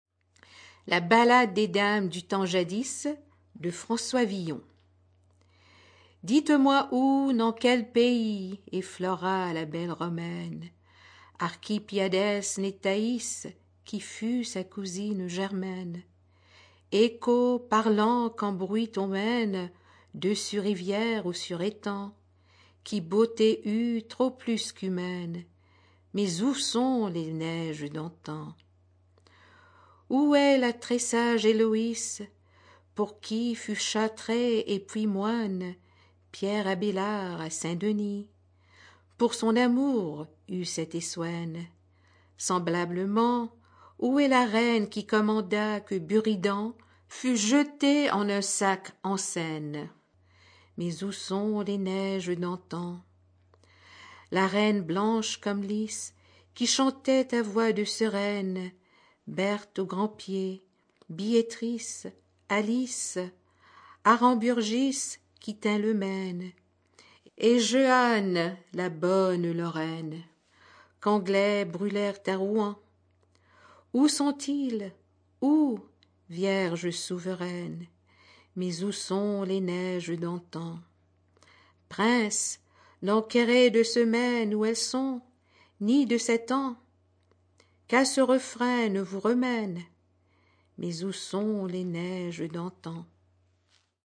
Audio non-musical
poetry